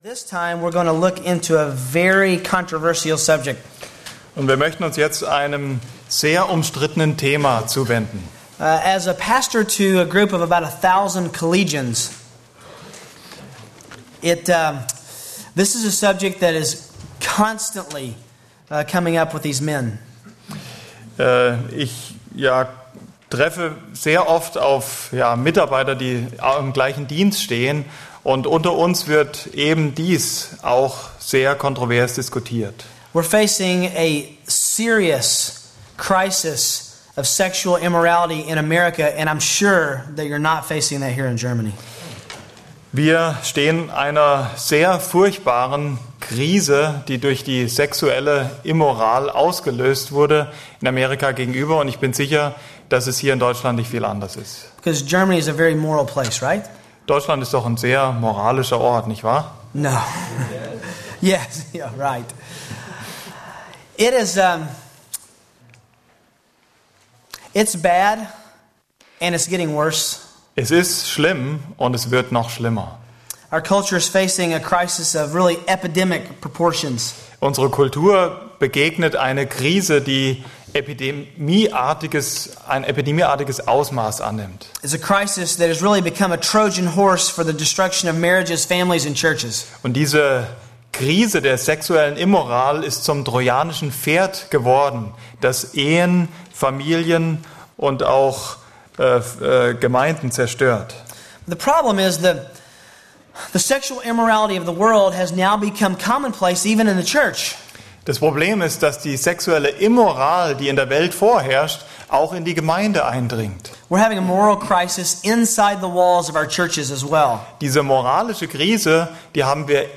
Hirtenkonferenz Vortr�ge 17 Vortr�ge englisch/deutsch auf 2 CDs als MP3 Von der Hirtenkonferenz der EBTC Berlin 2004 statt bisher 20 Euro
Beispielvortrag